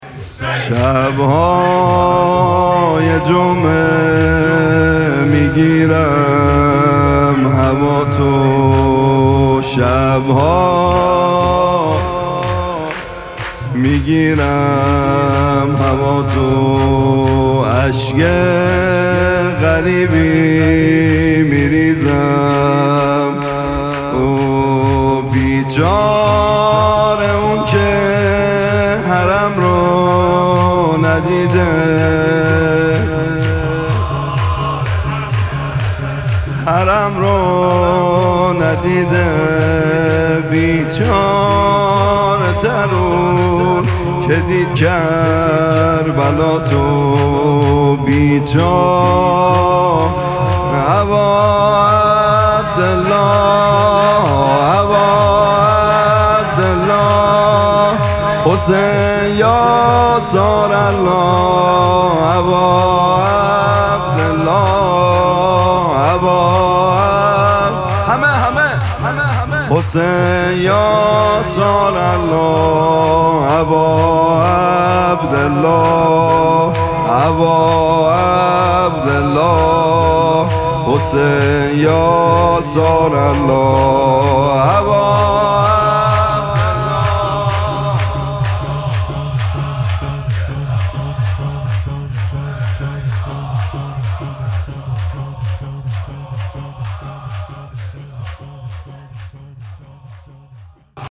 مراسم مناجات شب هفدهم ماه مبارک رمضان
شور